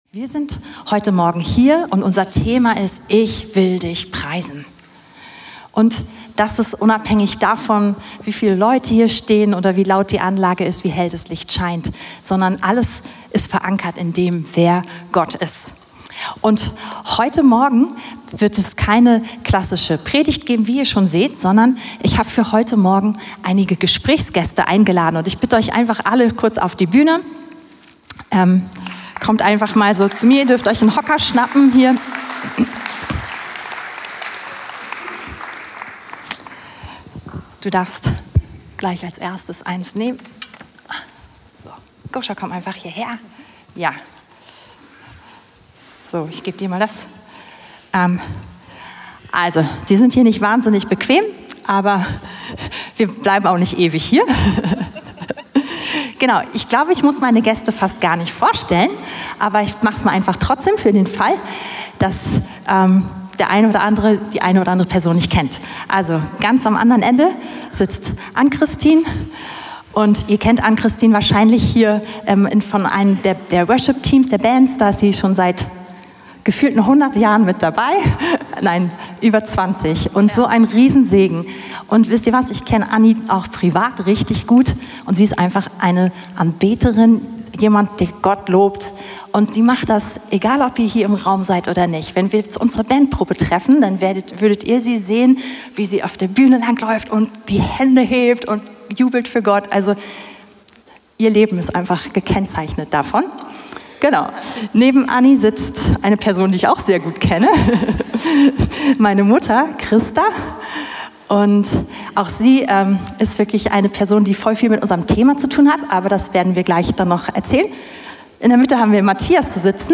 Predigten von Veranstaltungen der Gemeinde auf dem Weg, Berlin